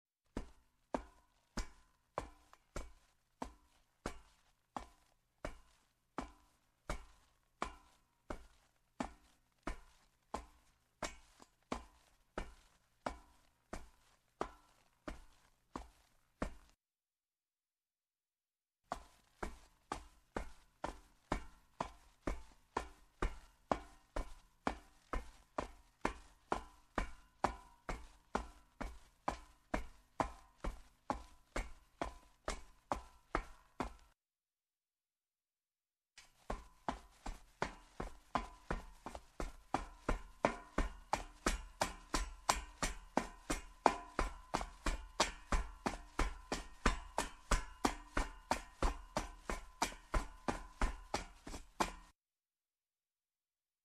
Металл звуки скачать, слушать онлайн ✔в хорошем качестве